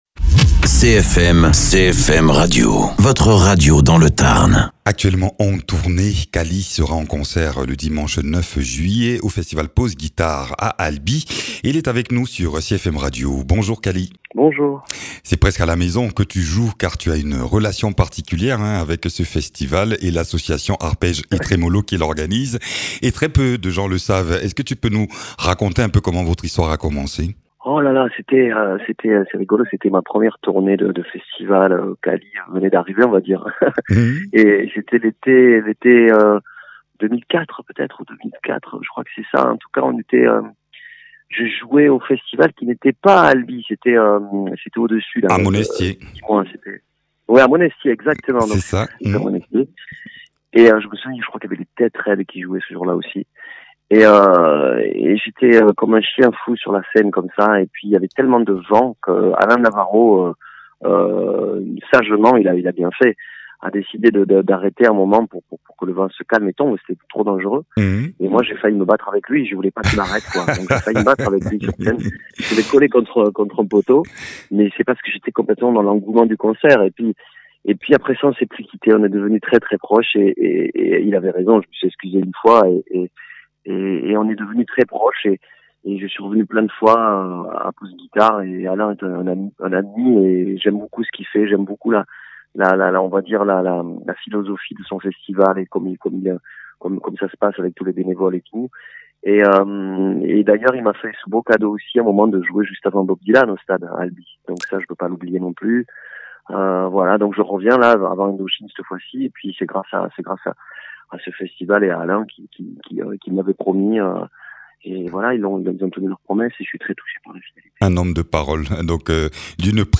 Interviews
Invité(s) : Cali, auteur-compositeur-interprète.